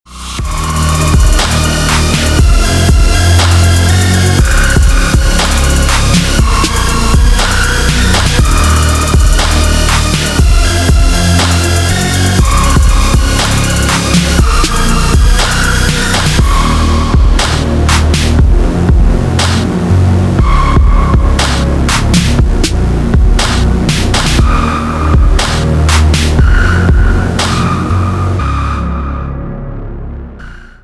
атмосферные
Electronic
без слов
Trap
пугающие
Bass
мрачные
Стиль: Witch House